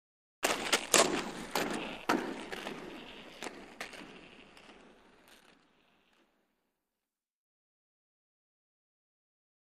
Roller Skating; Skating Away From Mic.